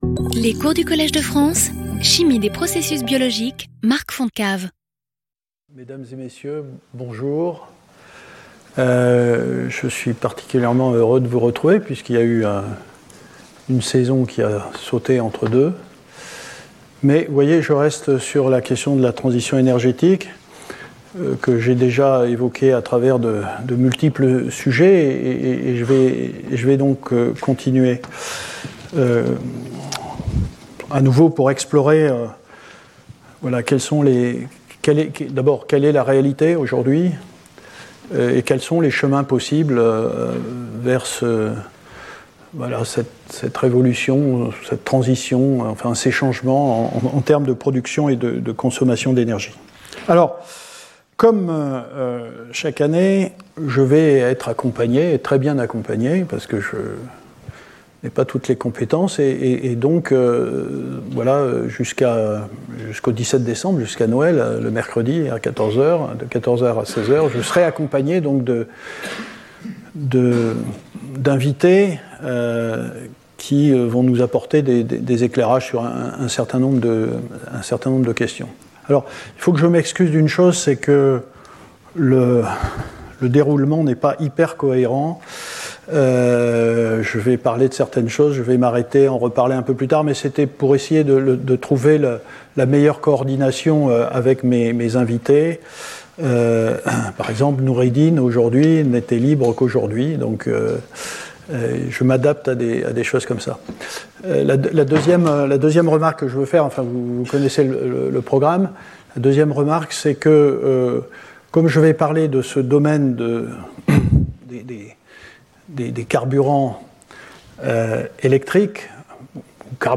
Intervenant(s) Marc Fontecave Professeur du Collège de France
Cours